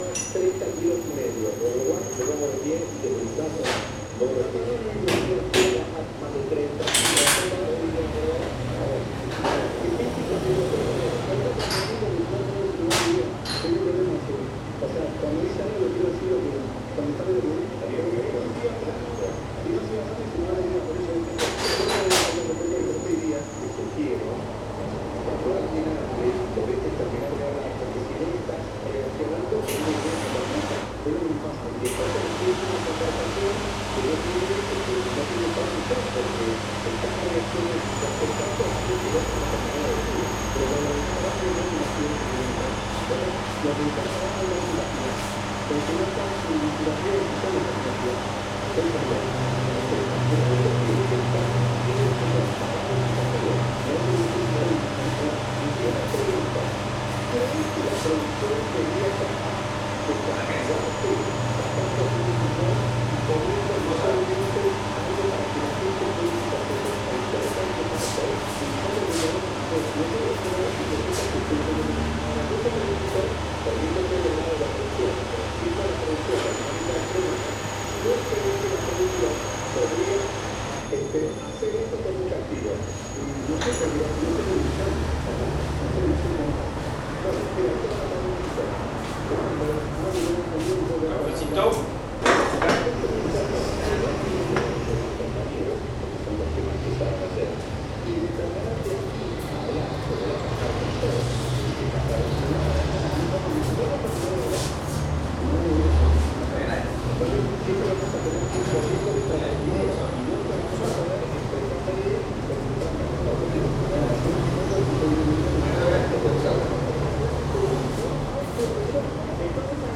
psr-cafeteria-san-benito.mp3